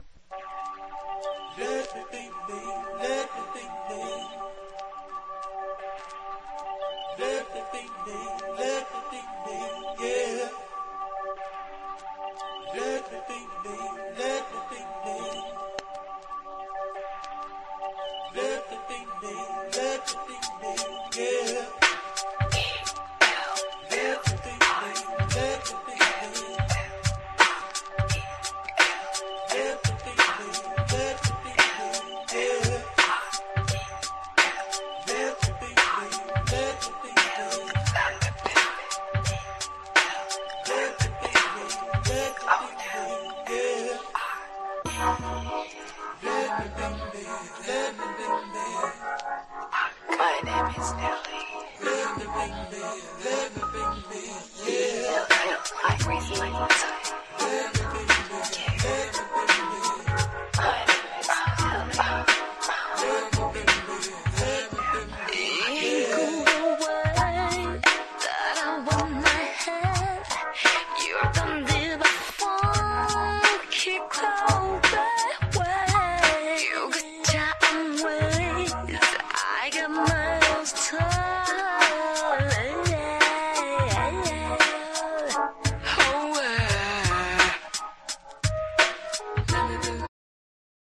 生音系のDJに人気の